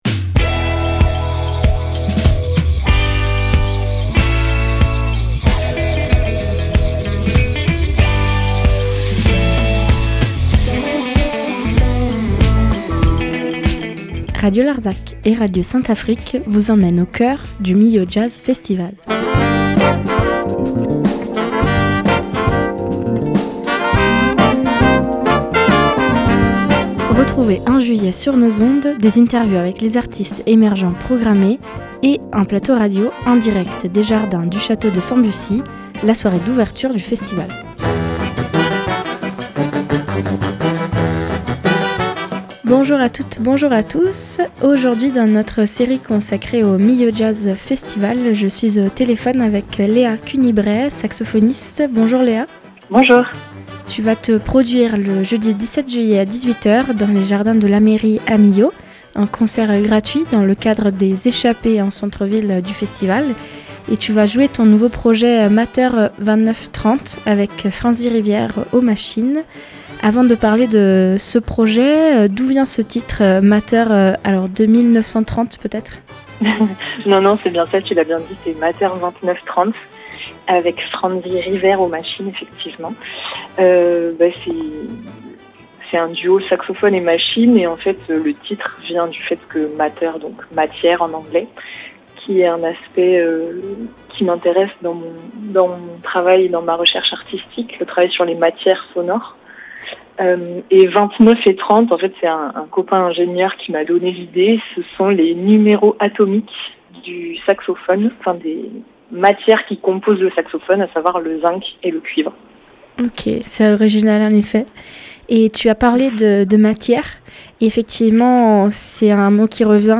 Millau Jazz Festival 2025 – Interview